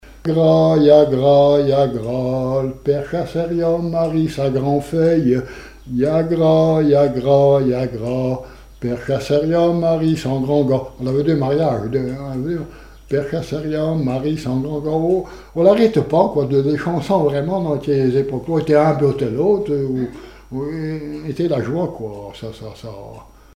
Mémoires et Patrimoines vivants - RaddO est une base de données d'archives iconographiques et sonores.
Chants brefs - De noces
Pièce musicale inédite